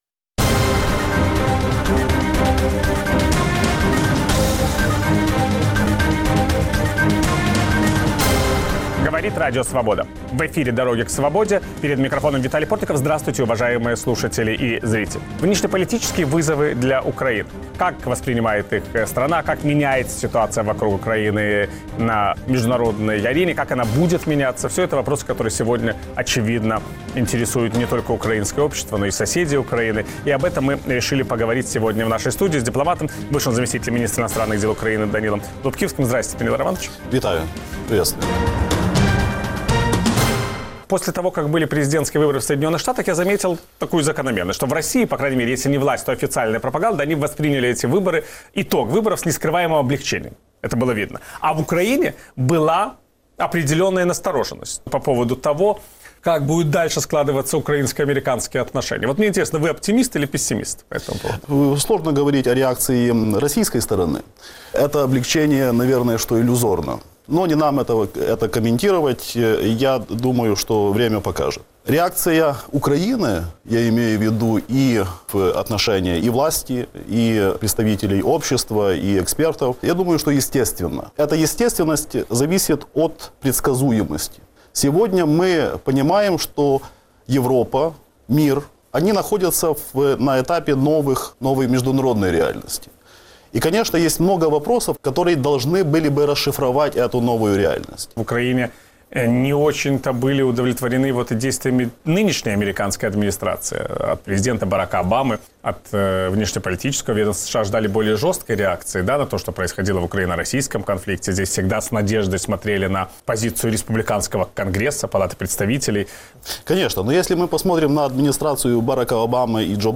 Виталий Портников беседует с бывшим заместителем министра иностранных дел Украины Данилой Лубкивским.